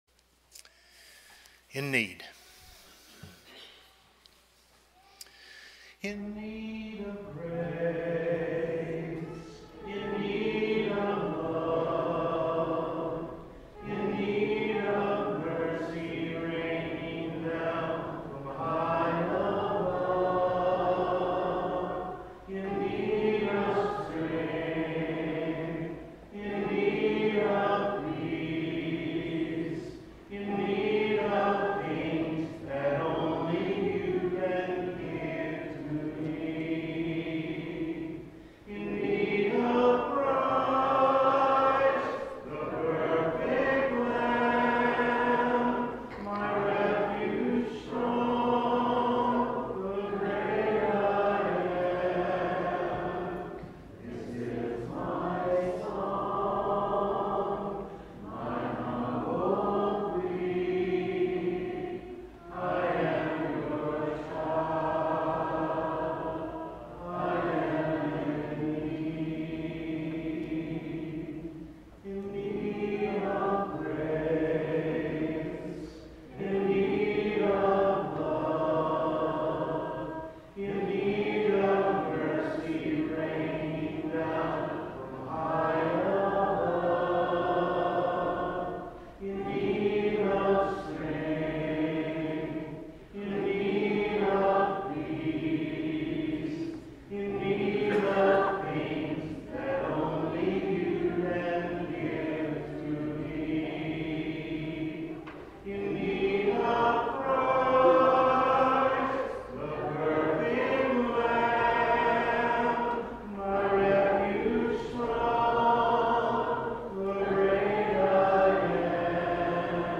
Romans 8:37, English Standard Version Series: Sunday PM Service